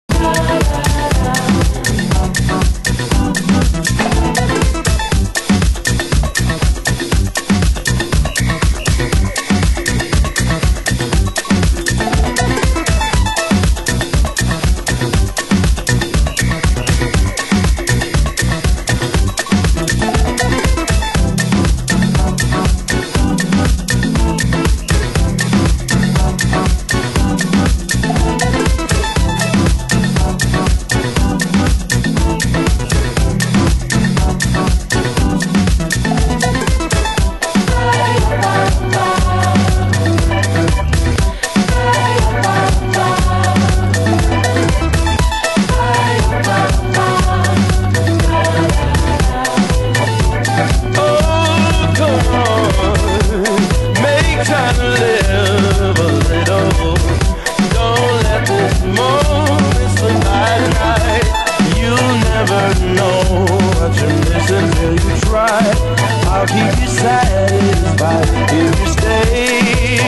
★DEEP HOUSE 歌
U.K Remix